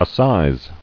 [as·size]